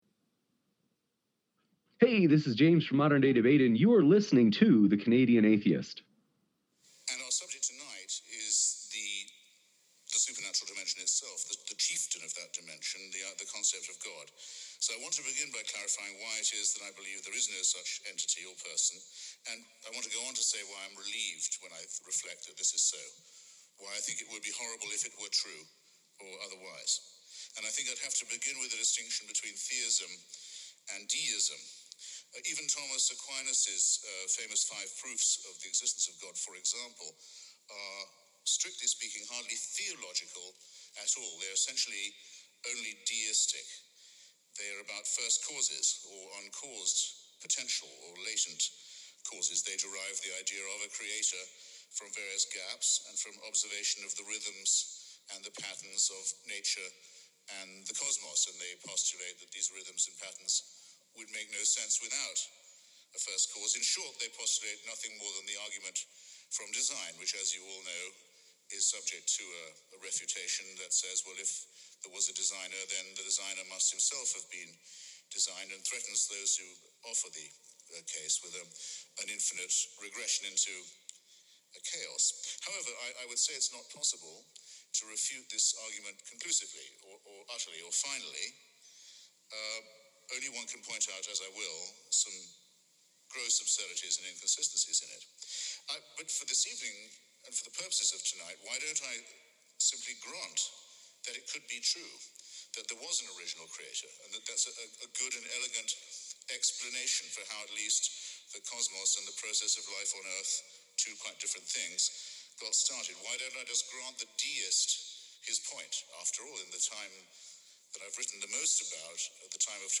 in studio…together.